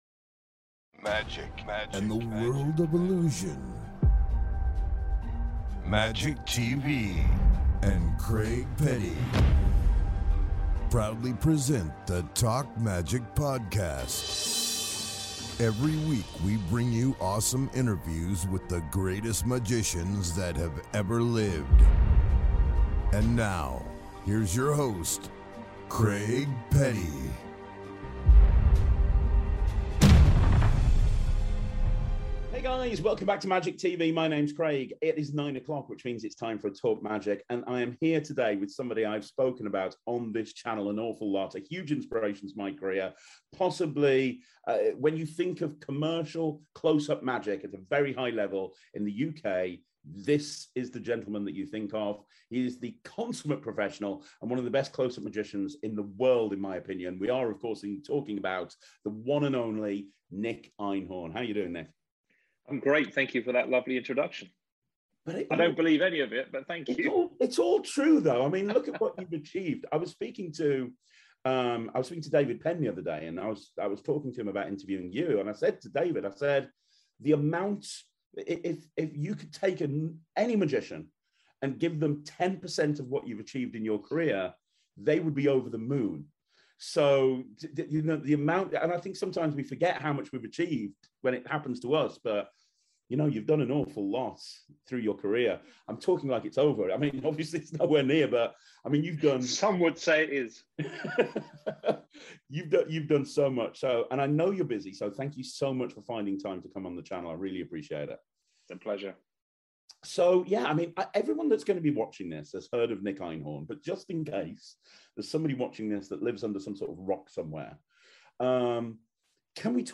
This interview is incredible!